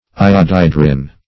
Search Result for " iodhydrin" : The Collaborative International Dictionary of English v.0.48: Iodhydrin \I`od*hy"drin\, n. [Iod- + chlorhydrin.]
iodhydrin.mp3